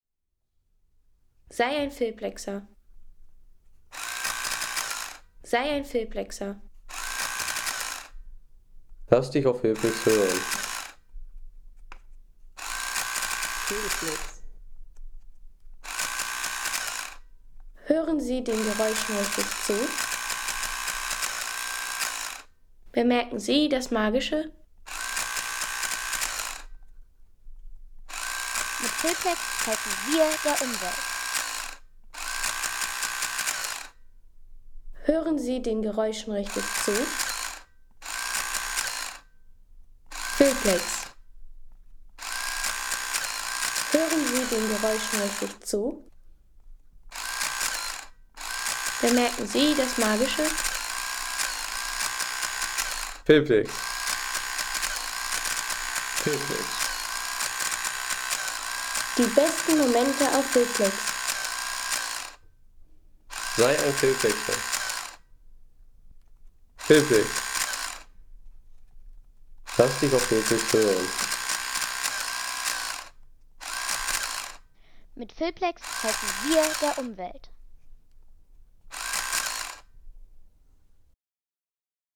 CASIO SR-5 Taschenrechner
Nostalgie pur – Der CASIO SR-5 Taschenrechner mit Druckfunktion aus ... 3,50 € Inkl. 19% MwSt.